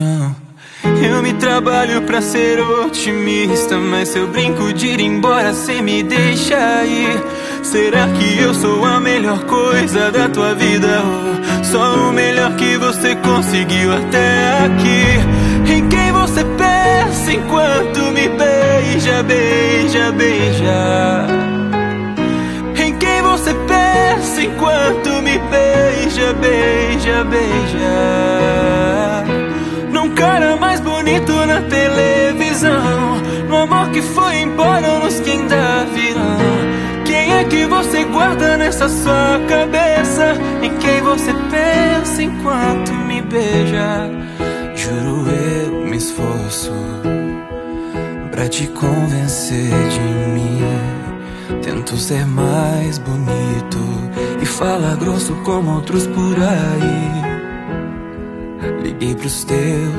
(Mix/Mashup)